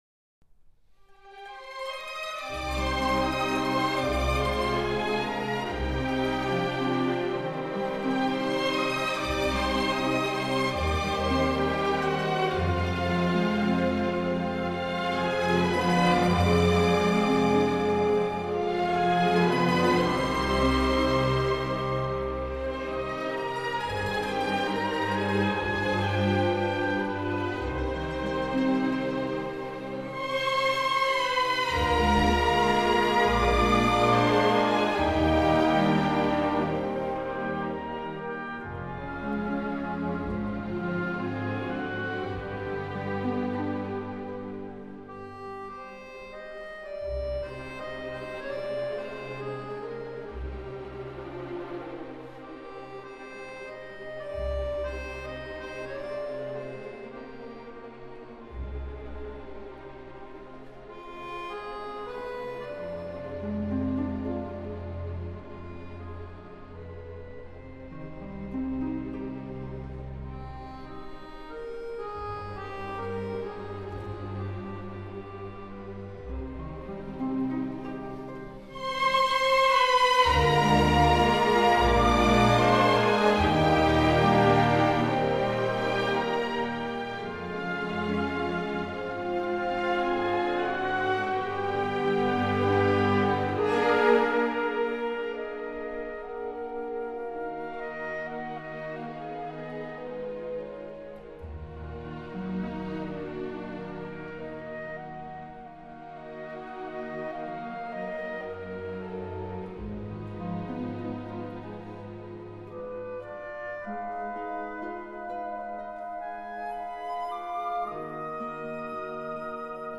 管弦乐的大量使用
正像是费里尼狂欢式的电影一样，热闹且梦幻十足。